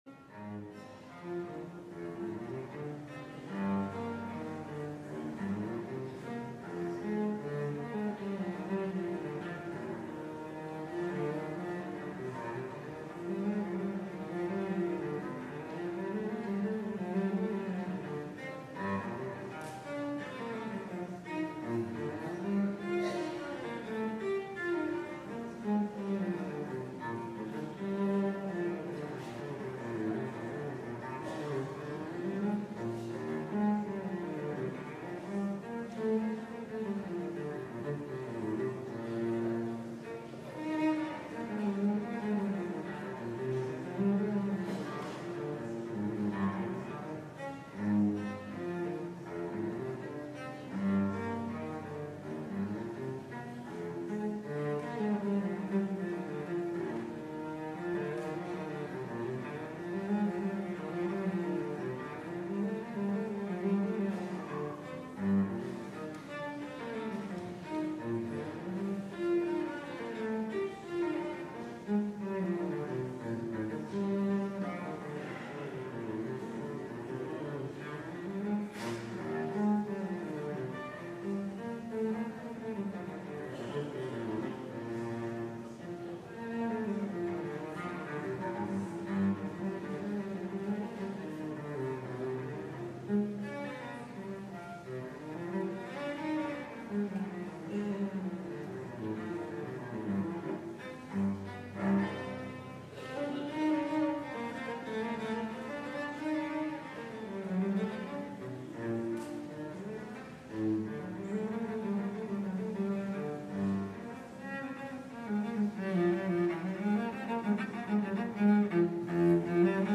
LIVE Morning Worship Service - Last Letter